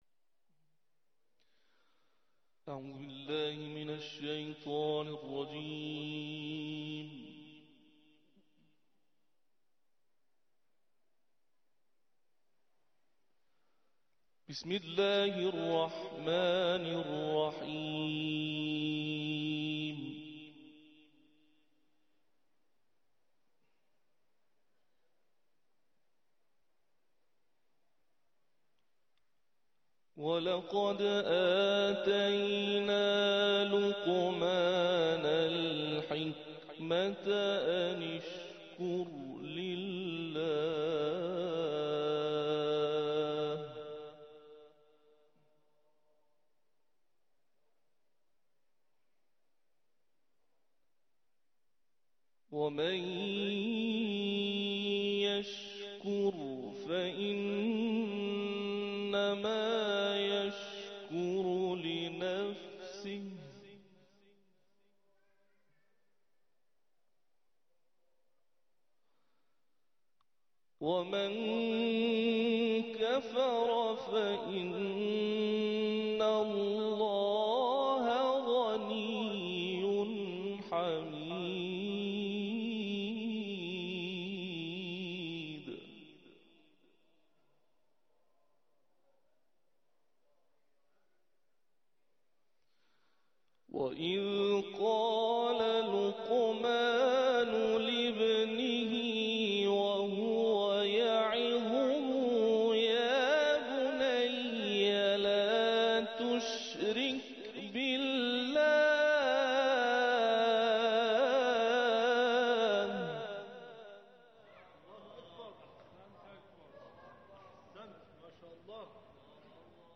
تلاوت ، سوره لقمان